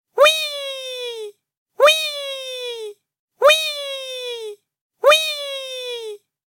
Mario Saying Wii Efecto de Sonido Descargar